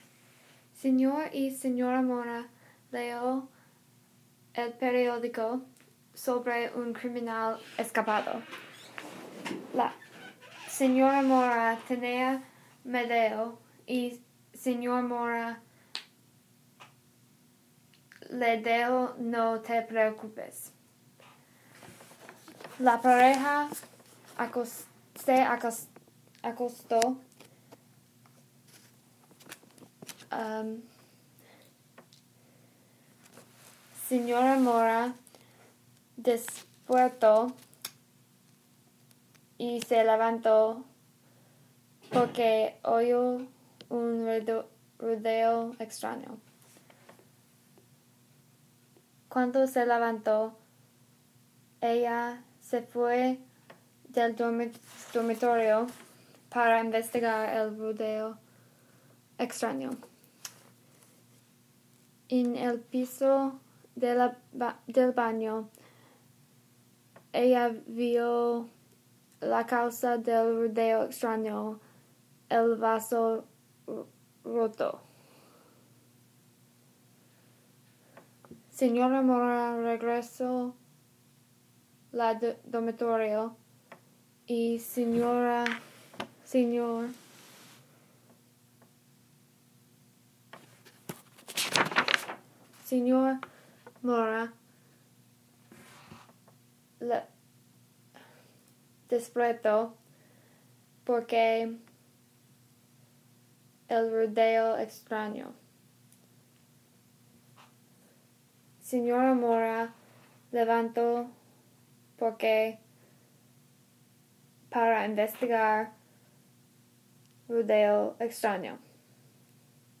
ruido en la noche